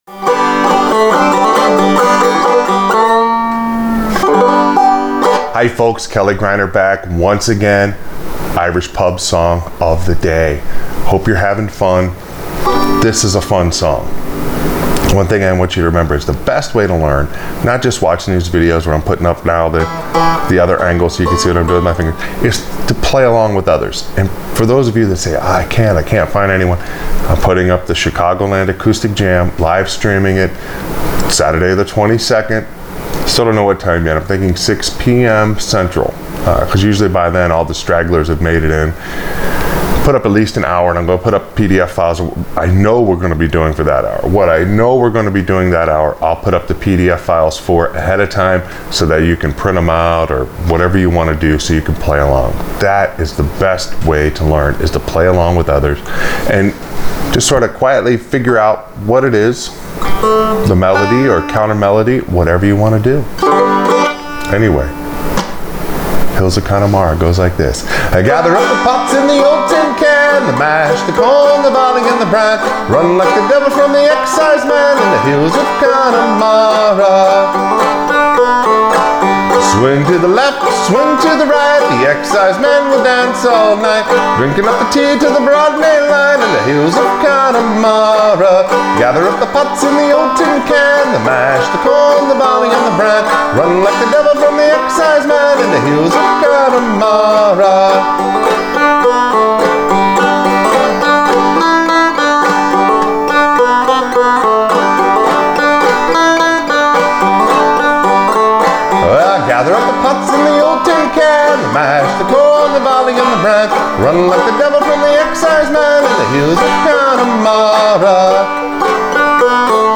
Irish Pub Song Of The Day – Hills Of Connemara on Frailing Banjo